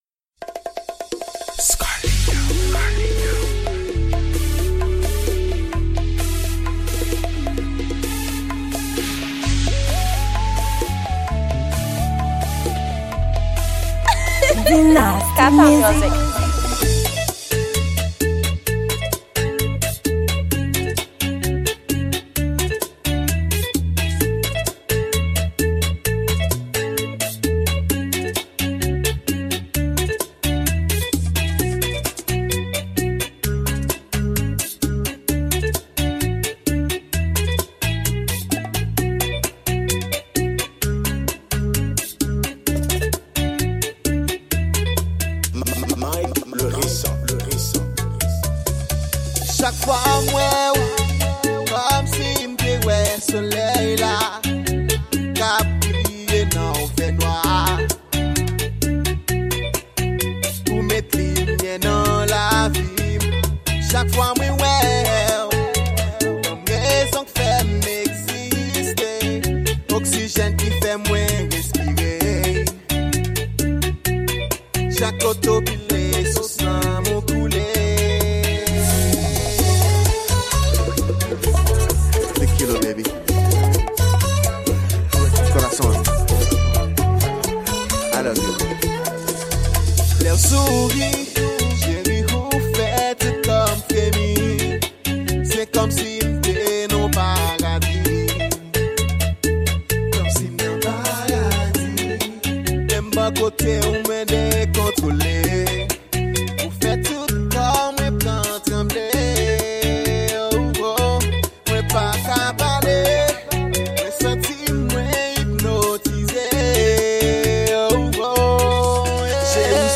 Genre: Batchata.